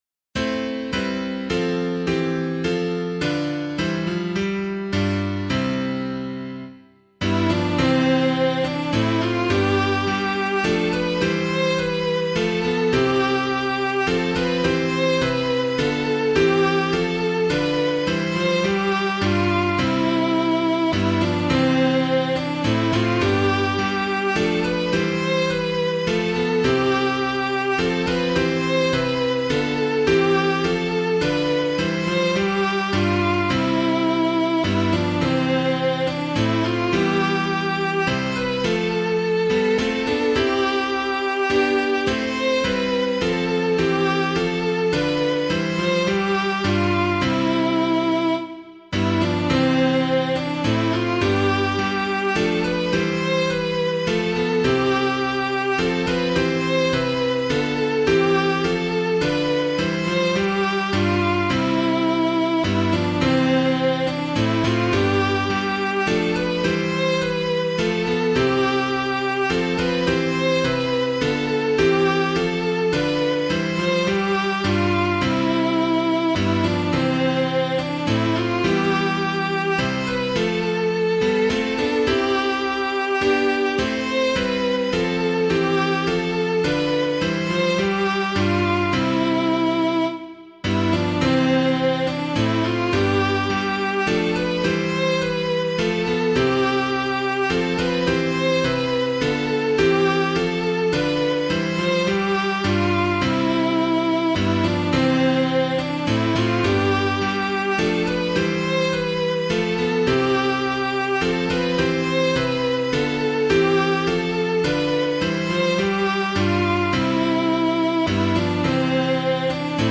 Hymn suitable for Catholic liturgy
The First Nowell [anonymous - THE FIRST NOWELL] - piano.mp3